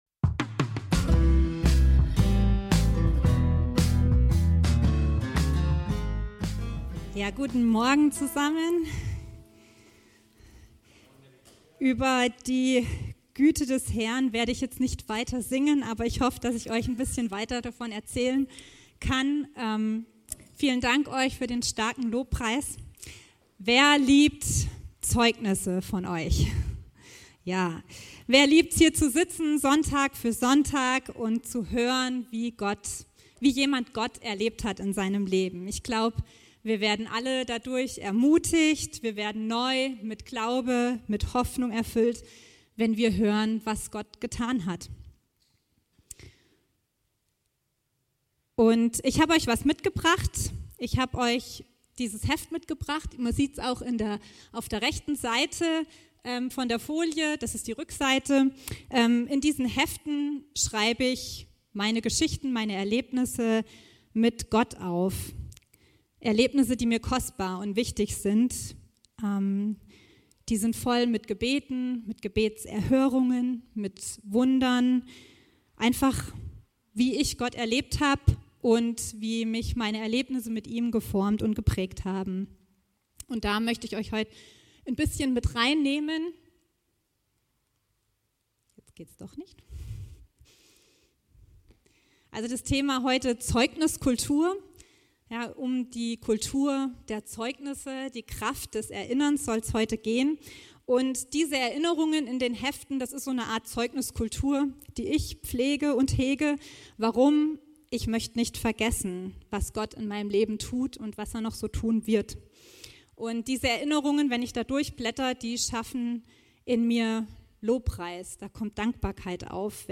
alle Predigten